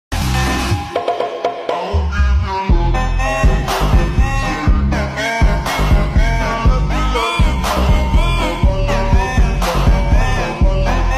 masih di suasana di kp halaman